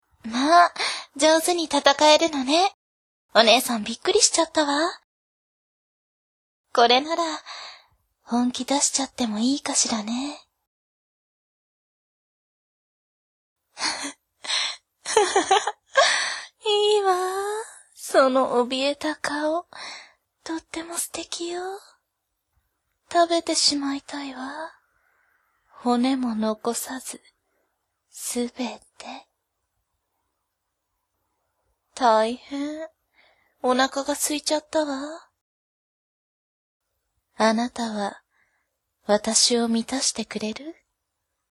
【 演じていただきました！ 】 名前：美澄（ミスミ） 年齢：？？歳（見た目は20代後半の女性） 性別：女性 妖刀：鋸歯刀（属性：？？） 穏やかで人当たりの良い元仙人の女性。